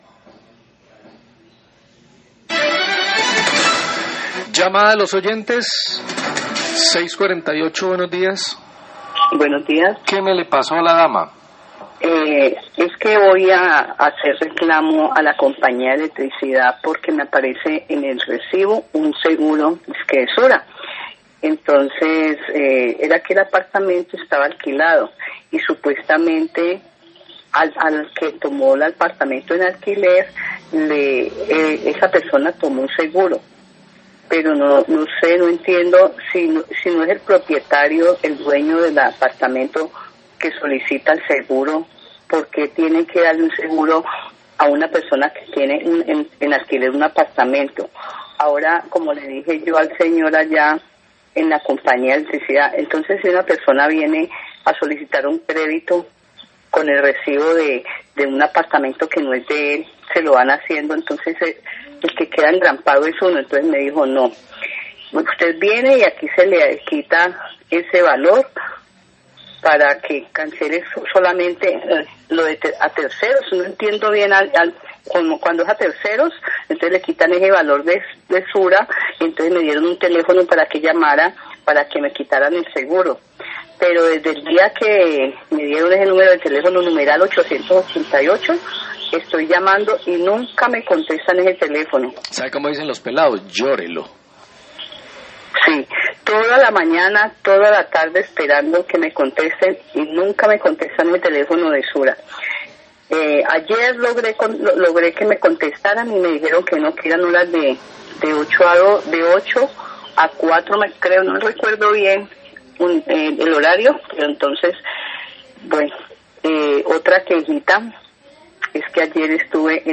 Oyente se queja por costo de un seguro incluido en su recibo de energía, Los Robles, 648am
Radio